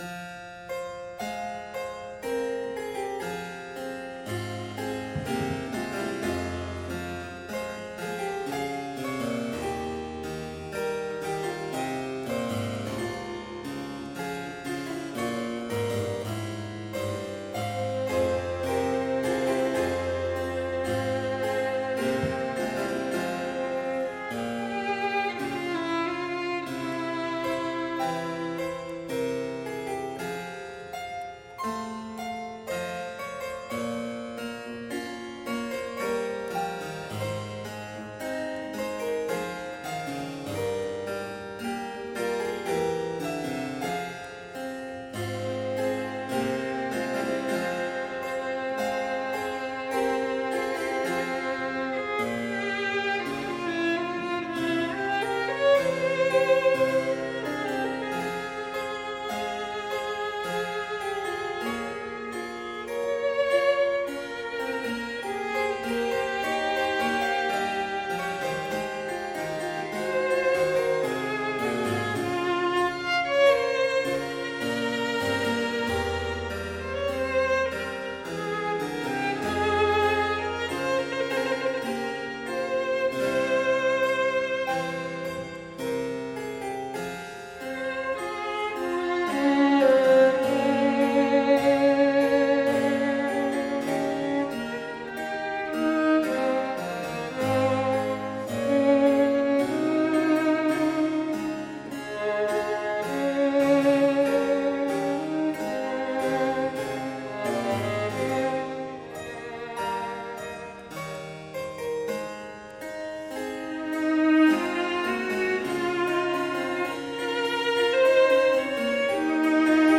Violin
Classical
Boston - Isabella Stewart Gardner Museum
harpsichord